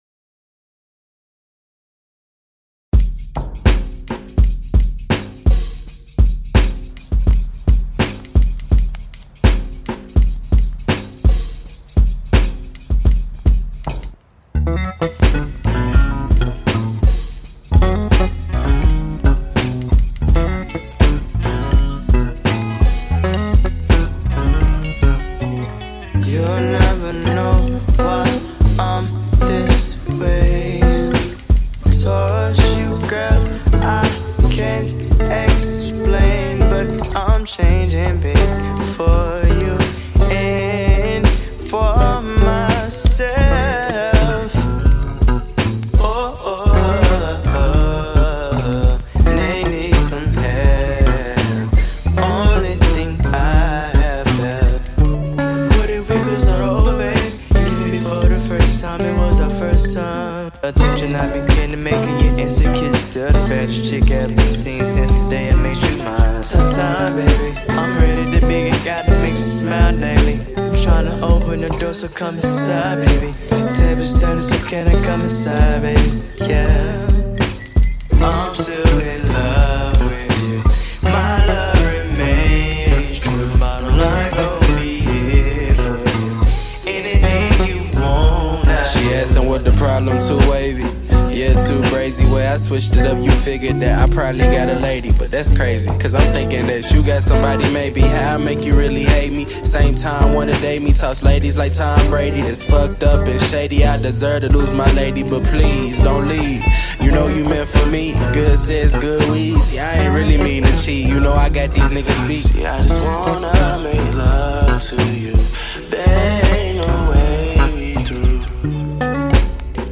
dreamy